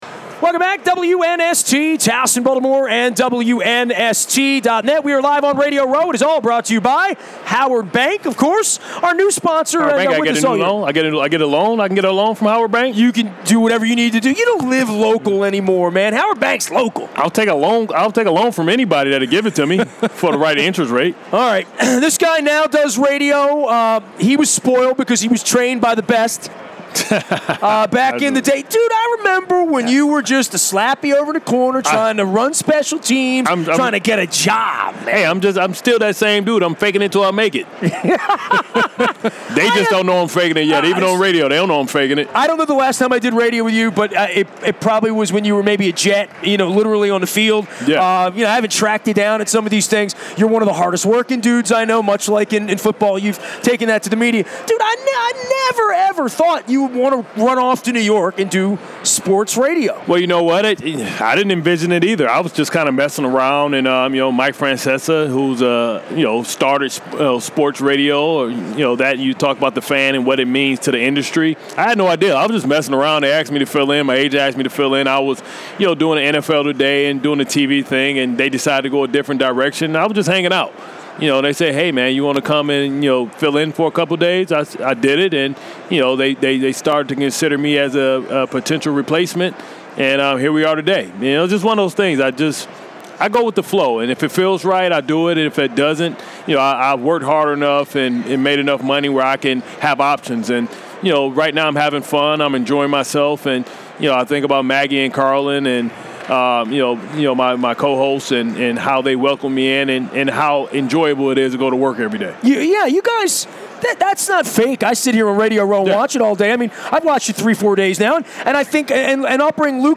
Radio Row in Minnesota Super Bowl 52